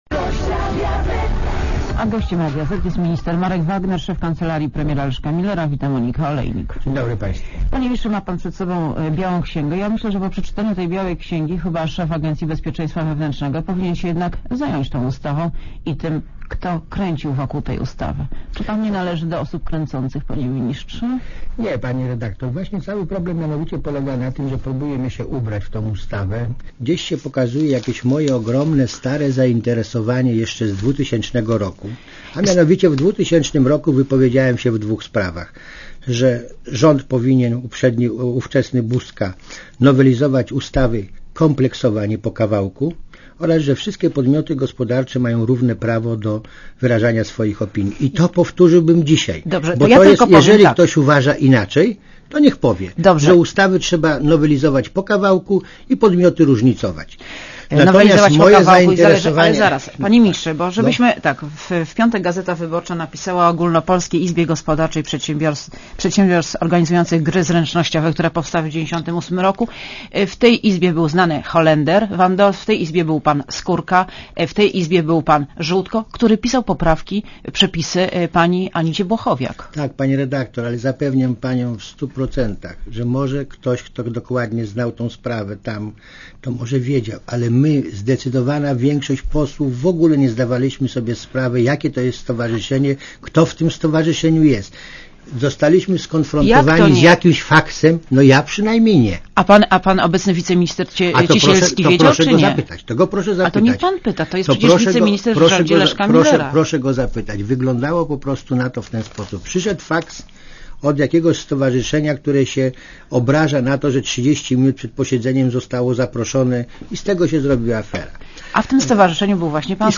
A gościem Radia Zet jest minister Marek Wagner, szef kancelarii prezesa rady ministrów. Wita Monika Olejnik.